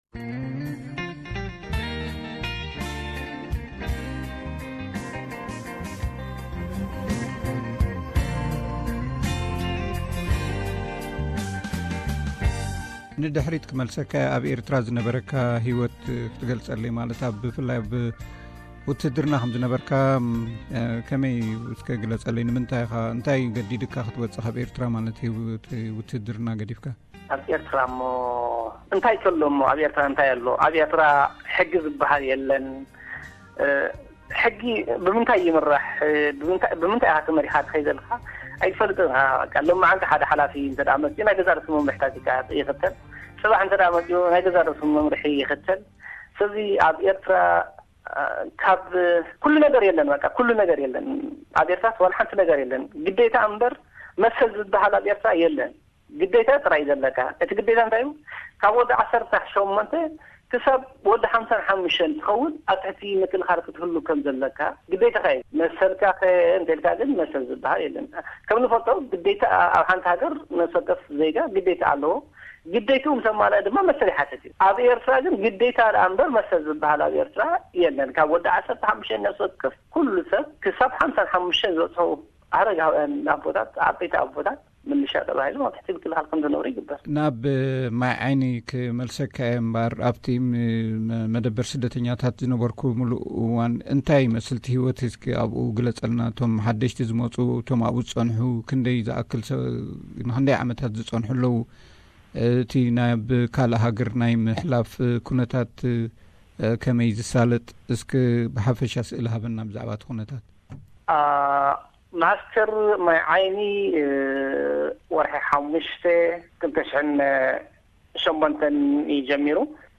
Interview with Eritrean Asylum Seeker in Indonesia Part 2